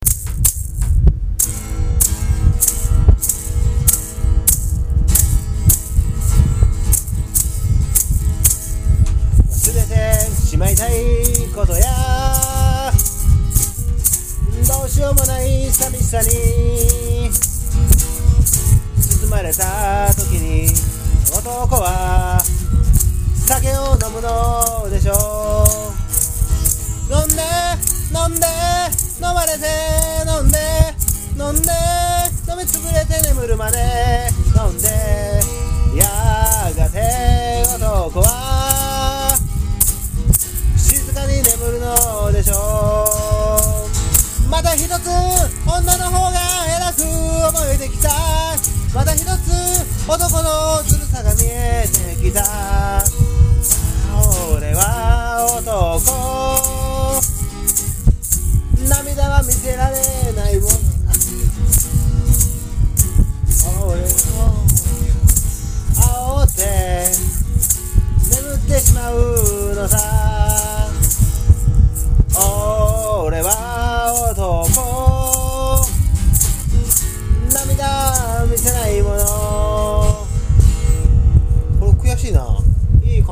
強く激しく畳み掛けるようなマラカスのリズム。
ブレイクの後、いよいよ最大の見せ場だ。変拍子の難しいリズム。フィルインの嵐。
この曲は何かを昇華するような演奏だった。